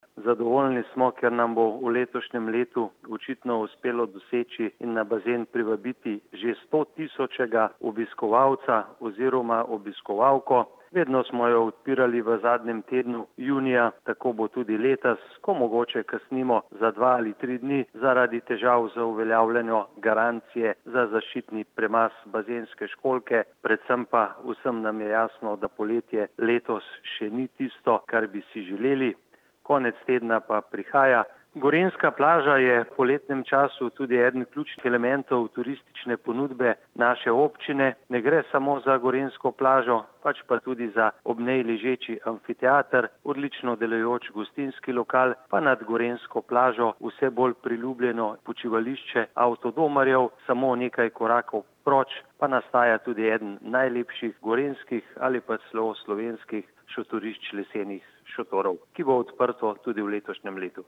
izjava_zupanobcinetrzicmag.borutsajovicogorenjskiplazi.mp3 (1,5MB)